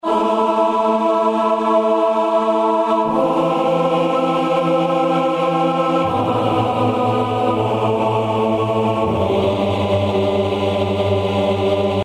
PS合唱团包男声合唱团天时合唱团
Tag: 80 bpm Hip Hop Loops Vocal Loops 2.02 MB wav Key : Unknown